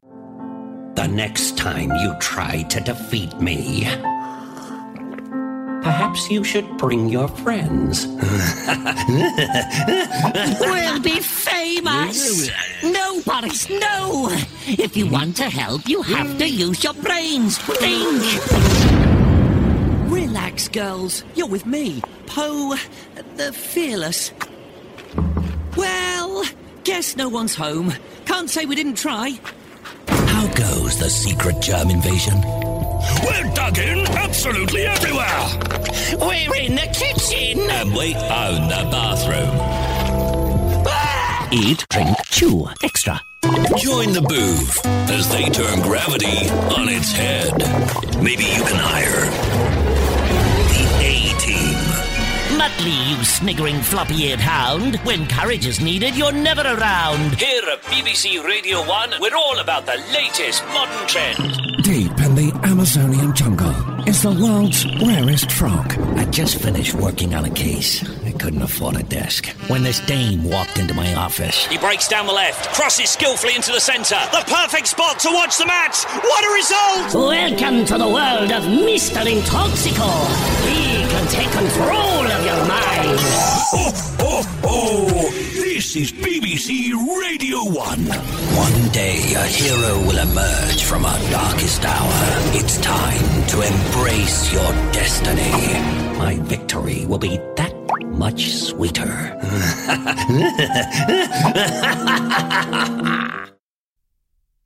Character Voiceover – Voice Creation | VoiceoverGuy
A 1 minute 44 second character voice compilation featuring gaming voices, cartoon styles and comedic characters.
From heroic narrators to unhinged goblins, and from blockbuster game villains to silly cartoon sidekicks — I lend my voice to worlds big and small, including my popular David Attenborough impression.